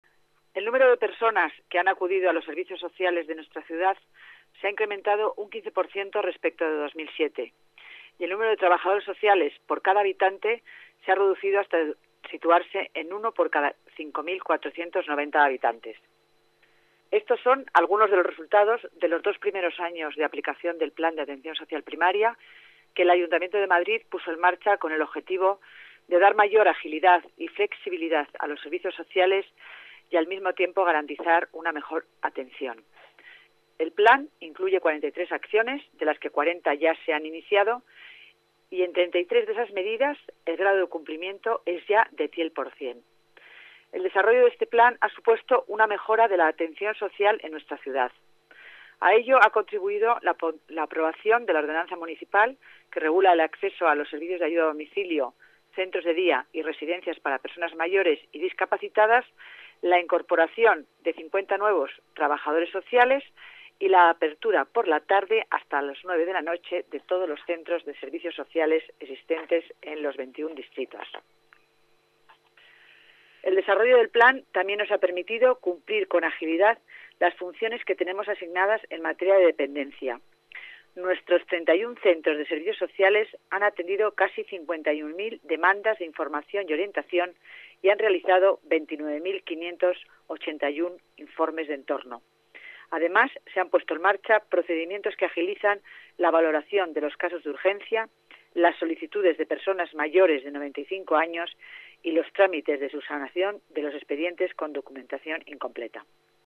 Nueva ventana:Declaraciones de Concepción Dancausa, delegada de Familia y Servicios Sociales